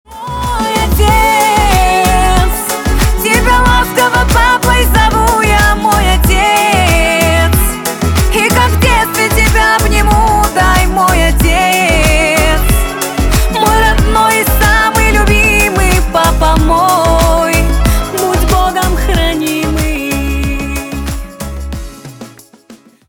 на русском восточные на папу